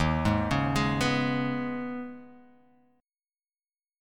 Eb7sus4#5 chord